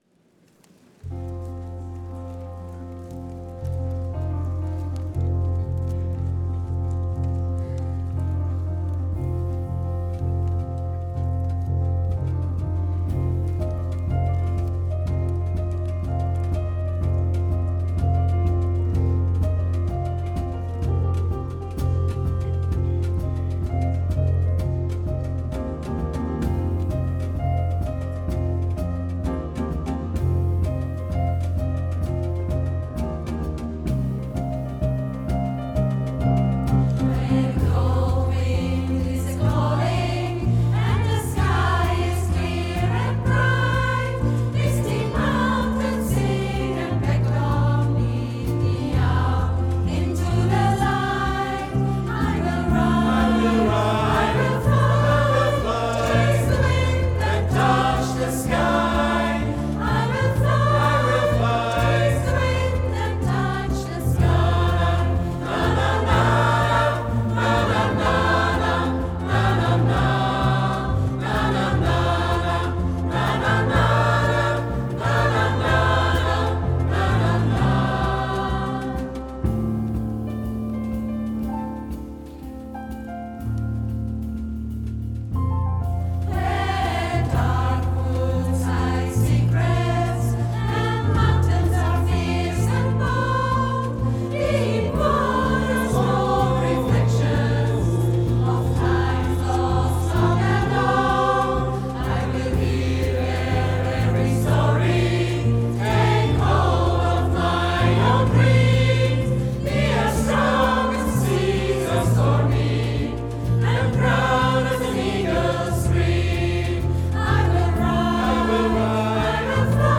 Firmung 2025 - Audioaufnahmen
SENTImento - Chor aus Kriens